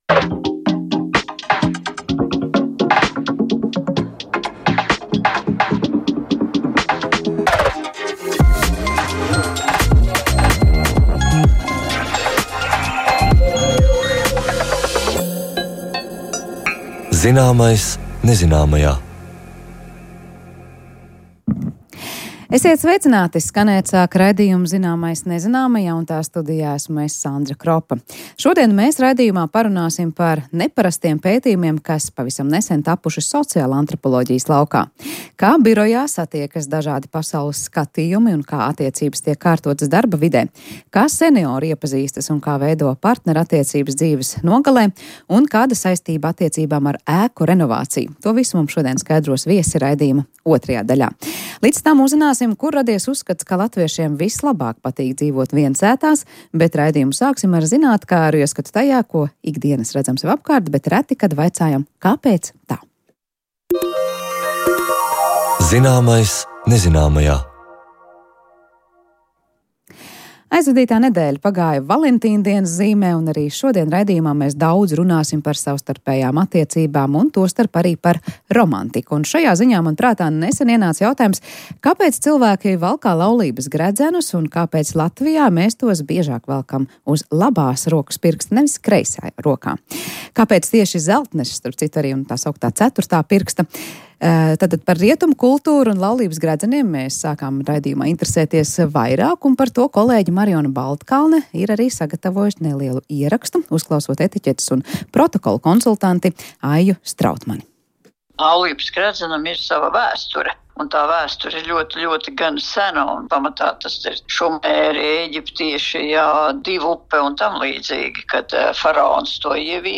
Raidījumā Zināmais nezināmajā sarunājas ornitologs